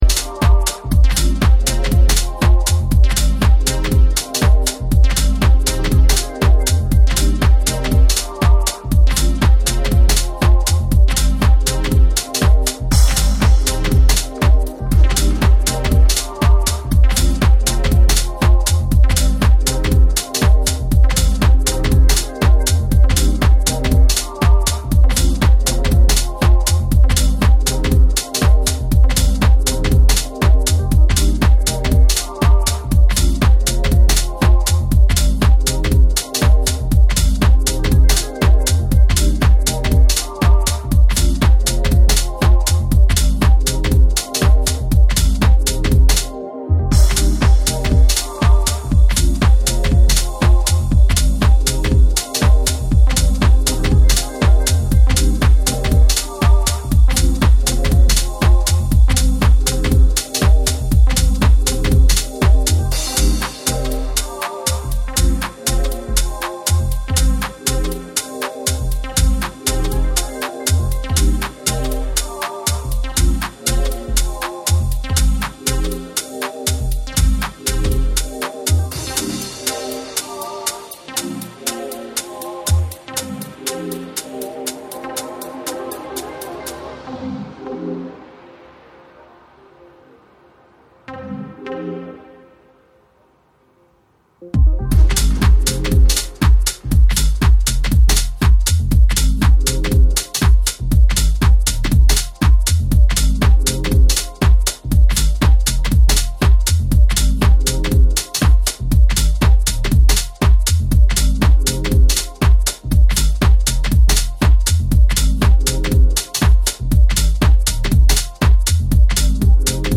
The rainforest of analogic machines